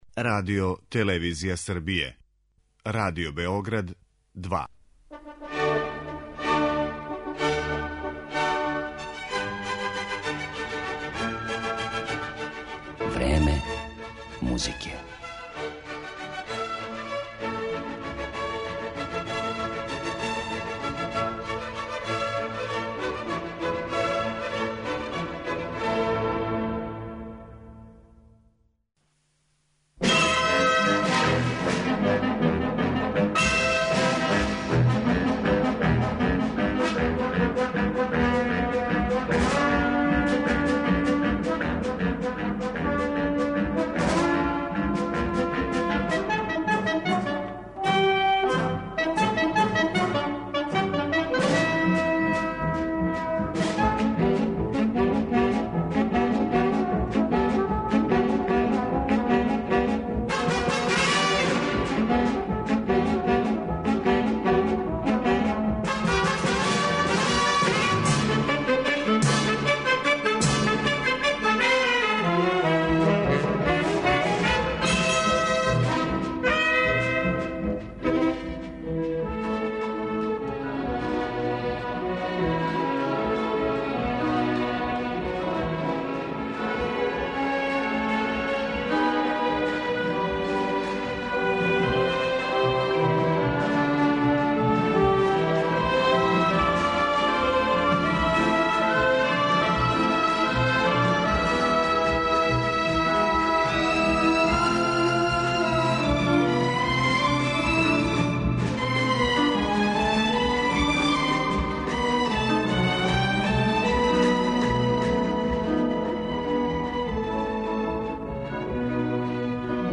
Гуно, Прокофјев, Берлиоз, Чајковски, Бернштајн, неки су од аутора који су својом музиком, а у форми опера, балета, симфонијских дела или мјузикла, описали љубав двоје младих из Вероне.